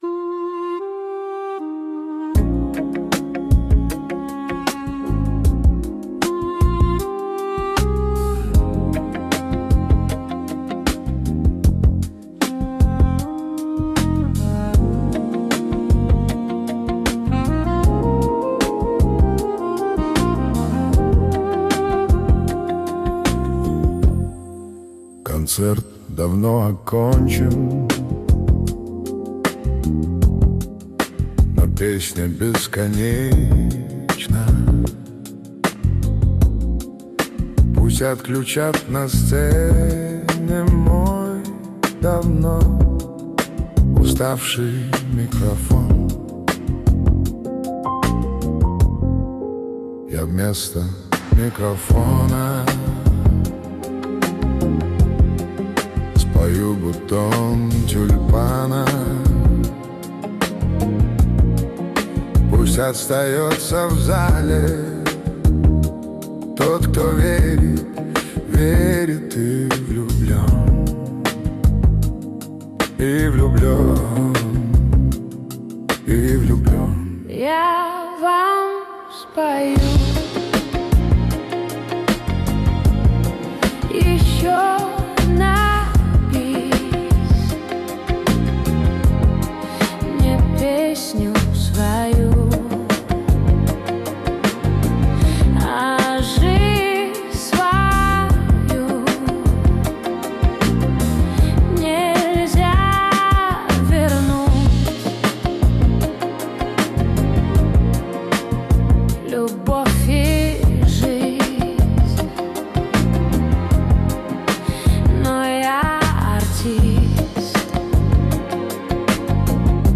Песни Суно ИИ